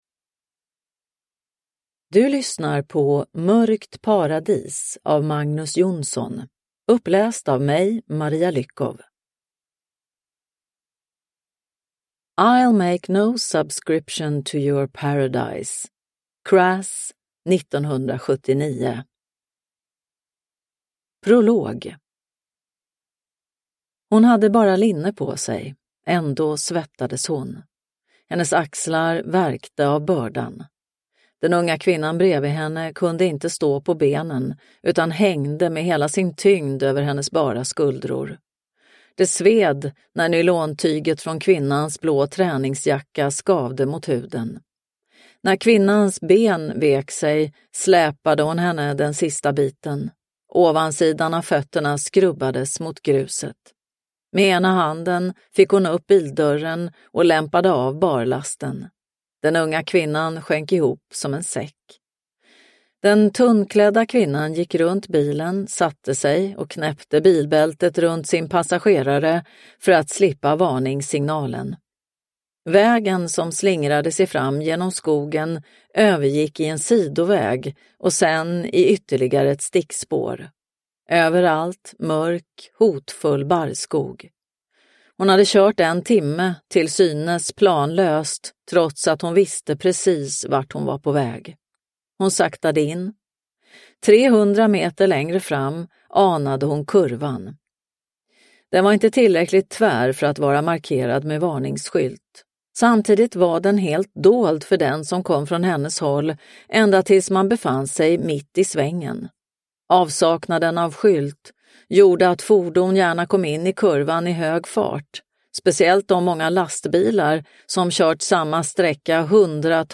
Mörkt paradis – Ljudbok – Laddas ner